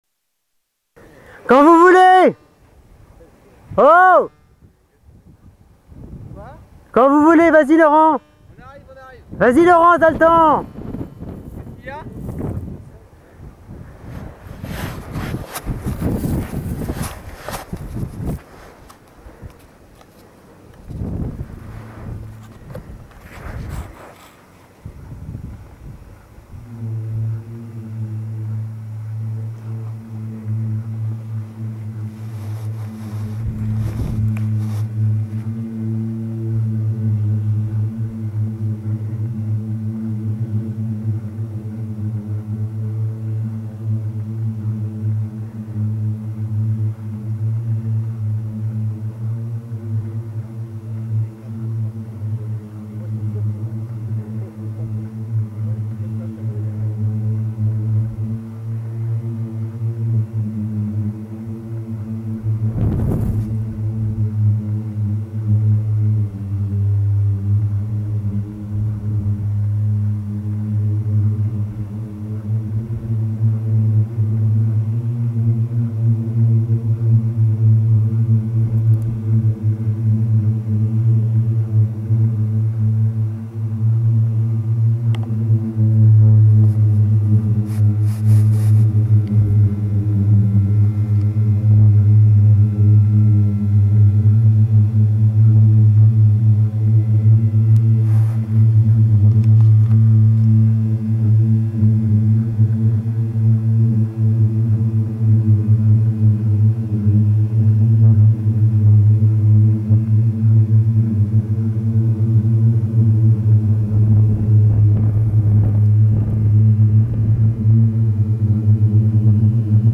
chant-dune.mp3